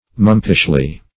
mumpishly - definition of mumpishly - synonyms, pronunciation, spelling from Free Dictionary
-- Mump"ish*ly , adv.